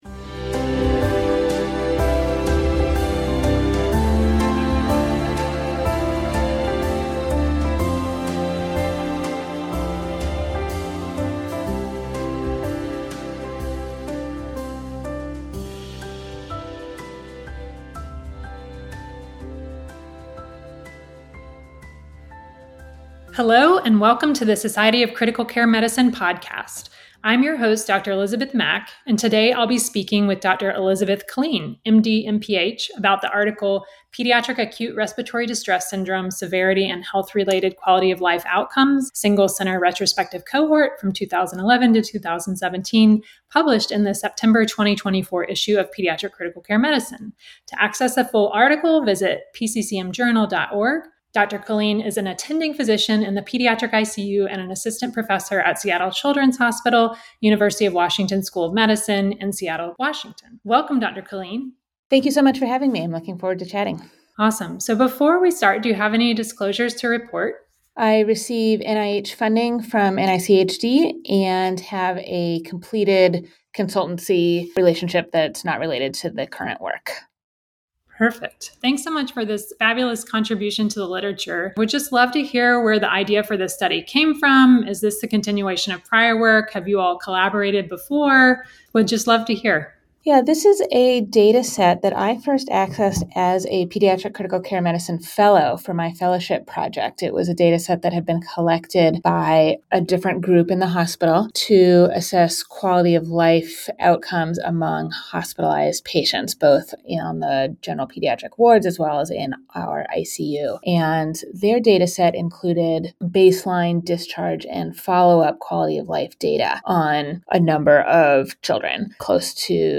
The Society of Critical Care Medicine (SCCM) Podcast features in-depth interviews with leaders in critical care. Experts discuss hot topics in intensive care with perspectives from all members of the critical care team.